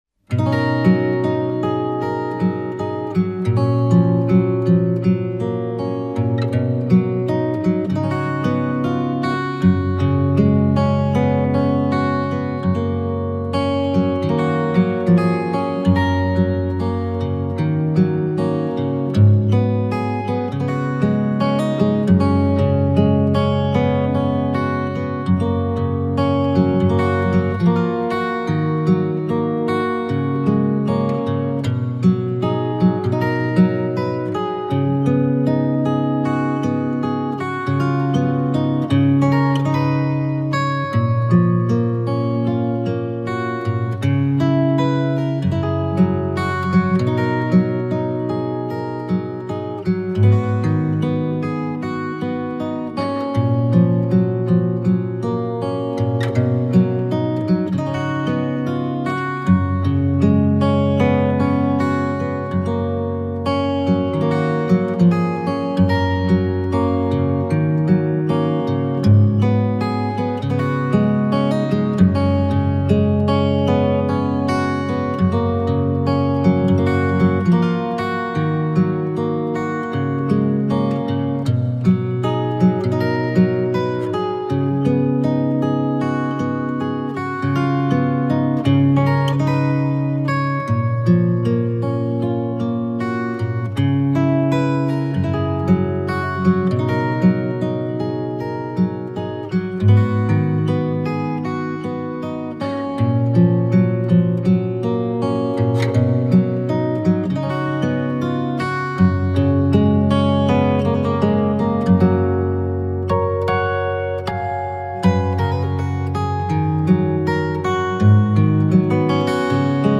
موسیقی بی کلام گیتار
آرامش بخش , گیتار , موسیقی بی کلام